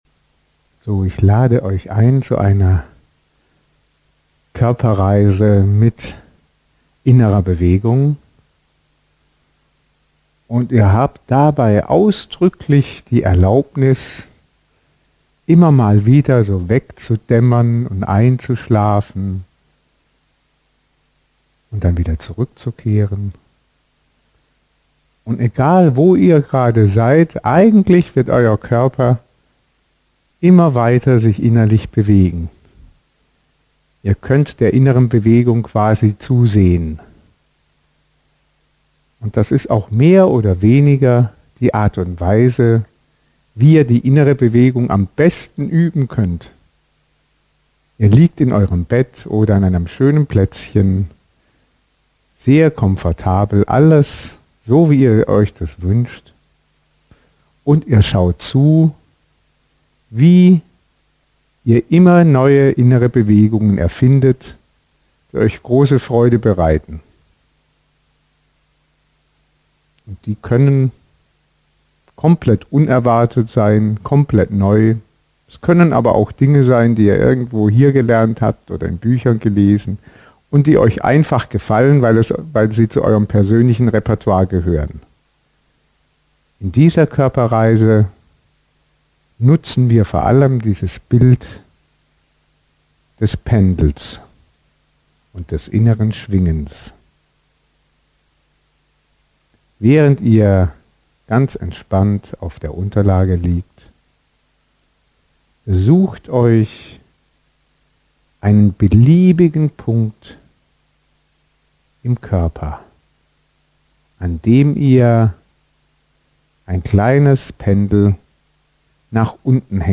Faszien-Coaching mit intendons®. Den Resonanzboden bereiten - Meditation, Körperreise, Tiefenentspannung, Verbundenheit